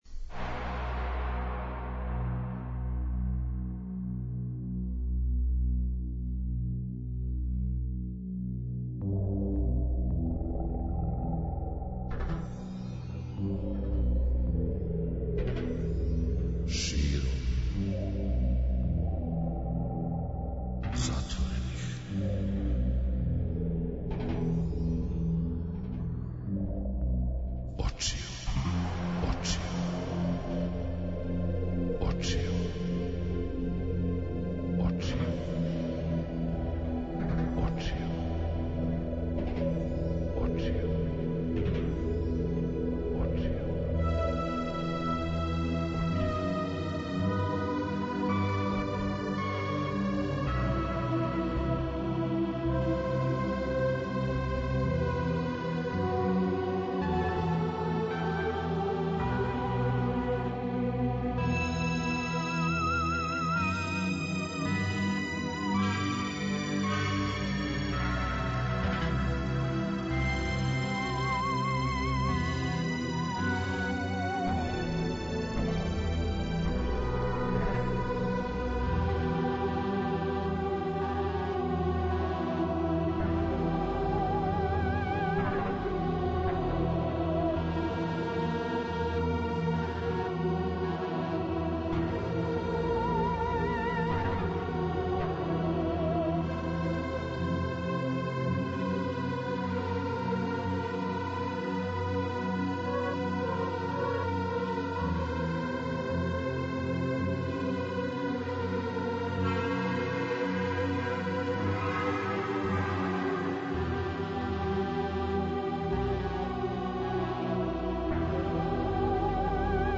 Овде стављамо три тачке и настављамо приче о симболима и њиховој употребној тежини међу народима, данашњици, прошлости уживо у ноћном програму.